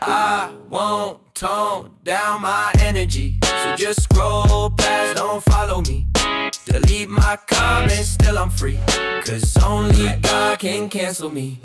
It sounds “cleaner” and makes it easier to focus on the lyrics.